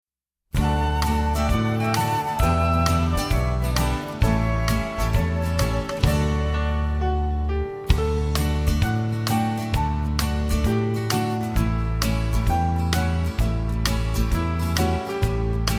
instrumental accompaniment music